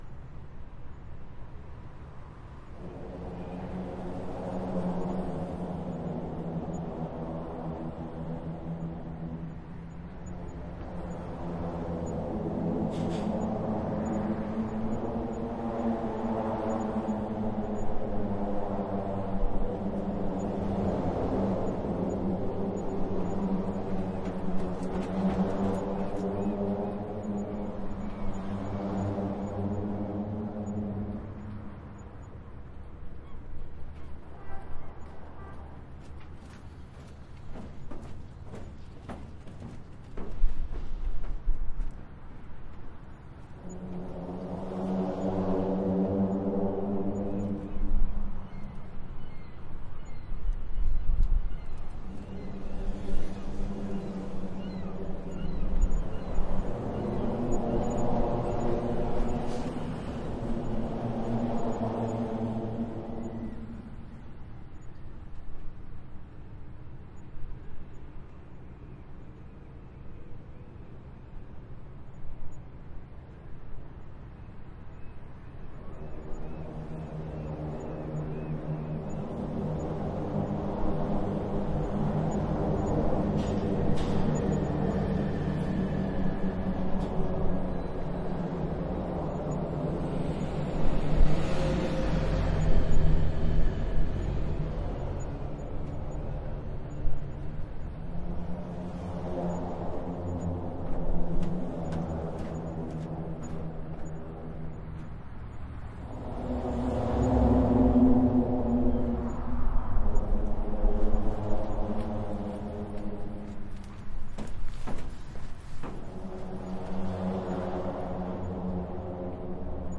河流边缘的适度波浪
描述：这是加拿大魁北克省圣洛朗河岸边的光波记录。这种立体声录音是使用声音设备USBPre2制作成Tascam DR680（spdif）和2个Studio项目C1在ORTF中，80hz低切。
标签： 圣洛朗 海浪 魁北克 现场录音 立体声 加拿大
声道立体声